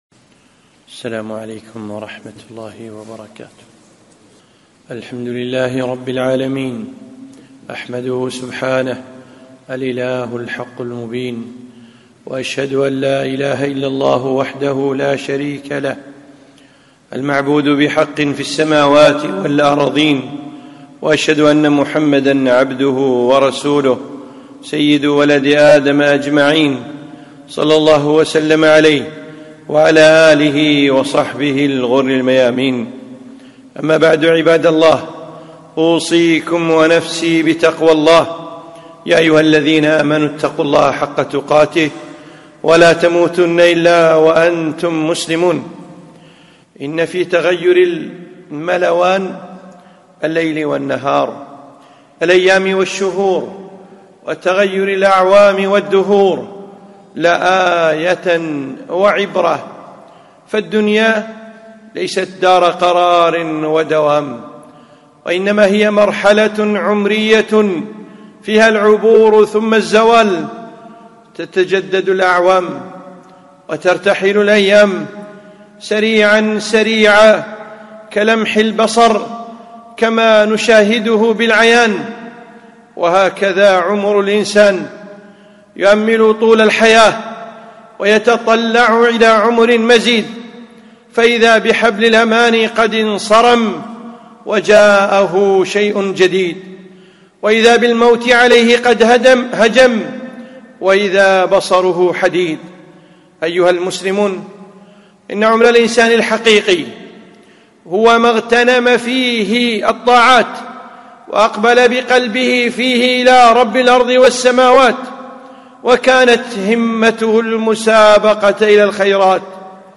خطبة - اغتنم عمرك في محرم